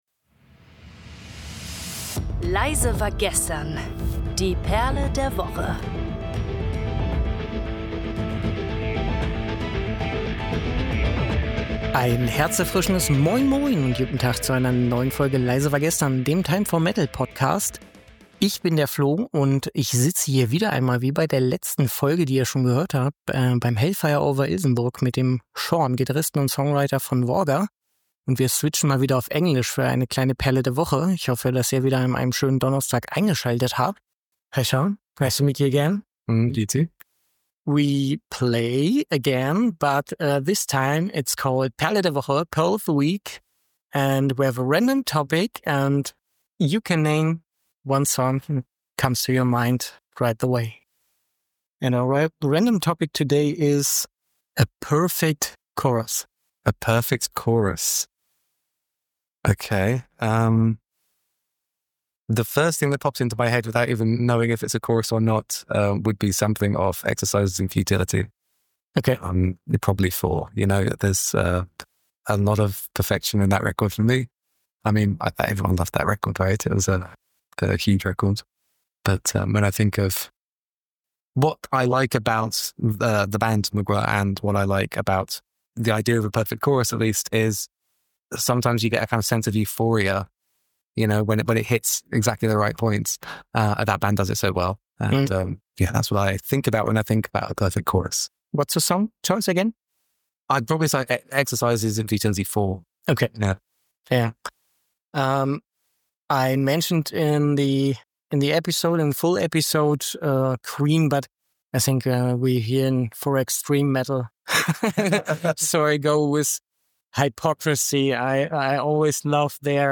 Direkt vom Hellfire over Ilsenburg Festival tauchen sie in die Welt des Extreme Metal ein und wählen ihre Favoriten – von Mgła bis Hypocrisy.
Live from Hellfire over Ilsenburg, they dive into the world of extreme metal and pick their favorites – from Mgła to Hypocrisy.